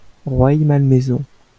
-Rueil-Malmaison.wav 来自 Lingua Libre 项目的发音音频文件。 语言 InfoField 法语 拼写 InfoField Rueil-Malmaison 日期 2018年6月23日 来源 自己的作品